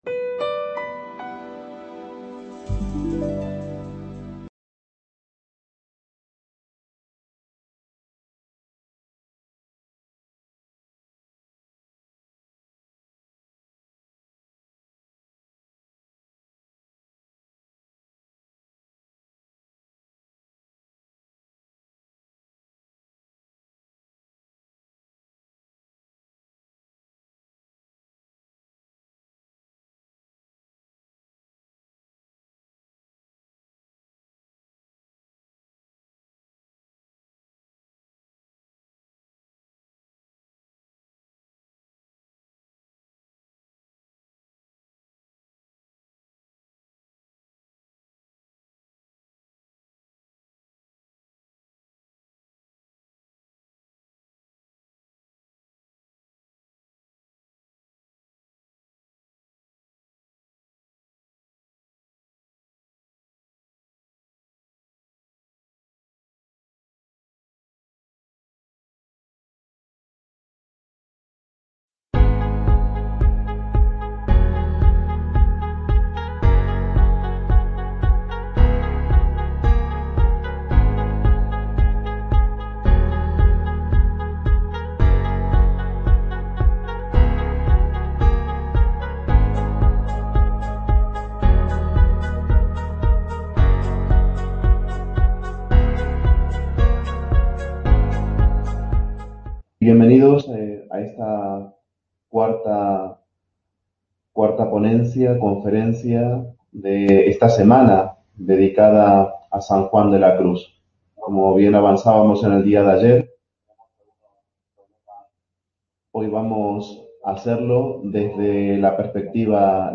imparte esta conferencia en el auditorio del Hospital de Santiago de Úbeda, dentro de la "43+1 Semana Sanjuanista"